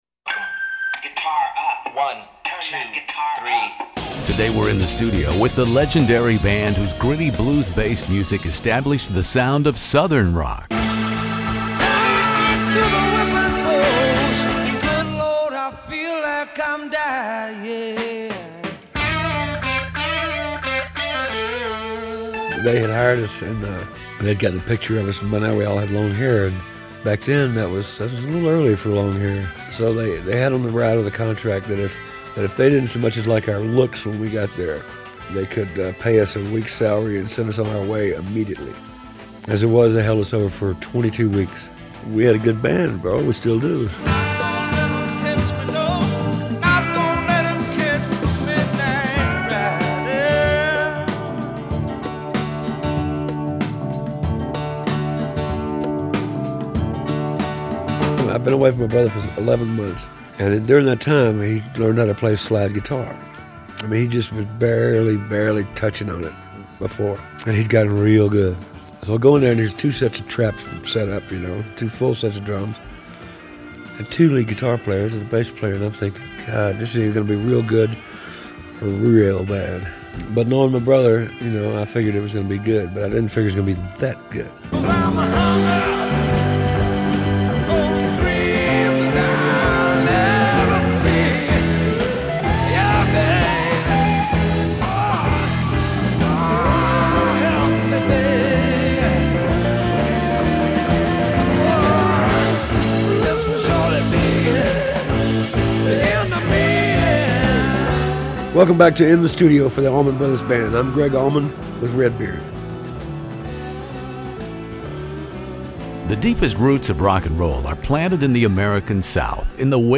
One of the world's largest classic rock interview archives, from ACDC to ZZ Top, by award-winning radio personality Redbeard.
The late Gregg Allman tells some wonderful stories in my treasured classic rock interview about the debut album and Idlewild South by the Allman Brothers Band.